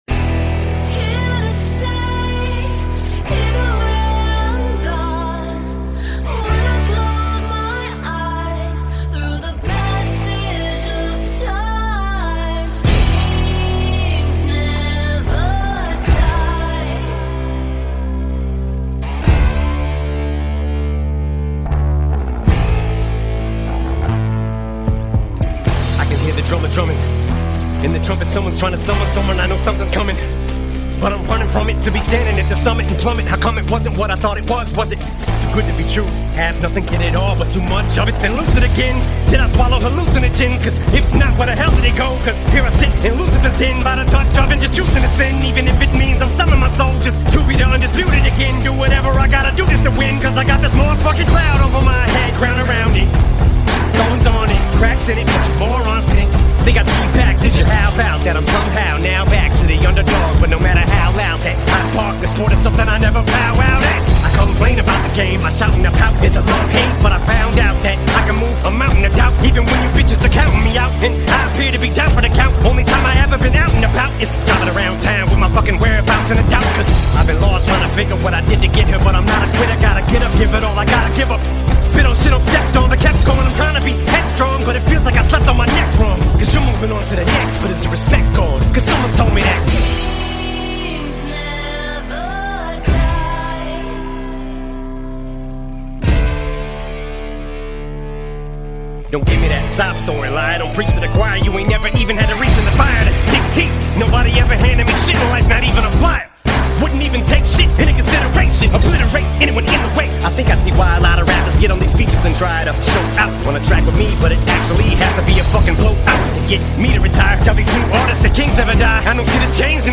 fusing Hard Rock & Hip Hop
utilizes a strong chorus line to this behemoth release